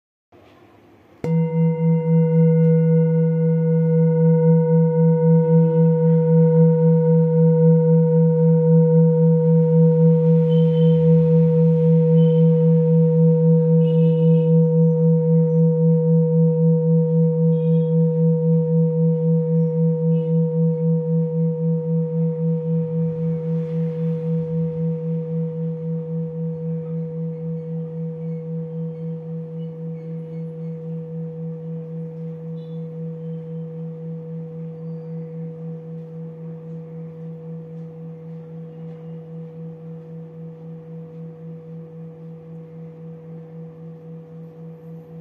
Jambati Singing Bowl-25786
Buddhist Hand Beaten Jambati Singing Bowl, with Super Fine Etching Carving, Select Accessories
Material Bronze
It can discharge an exceptionally low dependable tone.